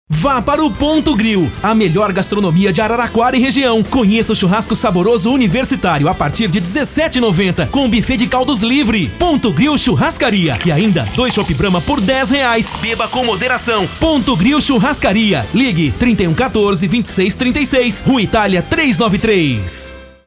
Spot de rádio